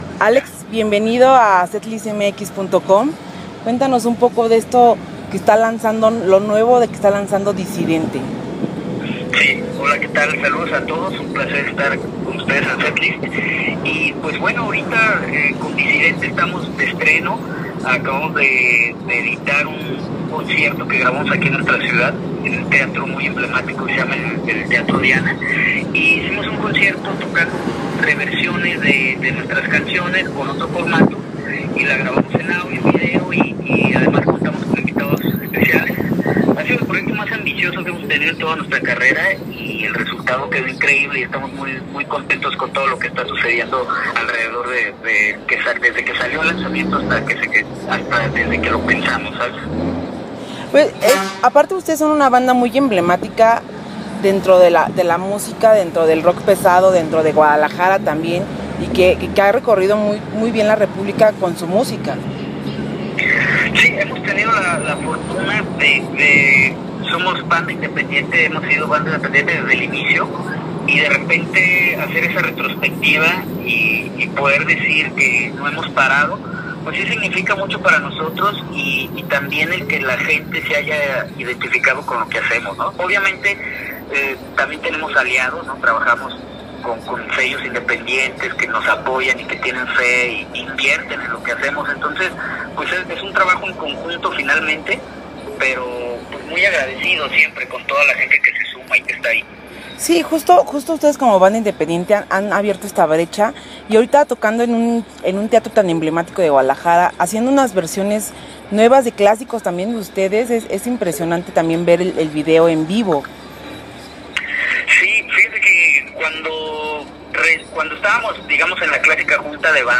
Platicamos con ellos sobre esa noche y lo que la banda está preparando.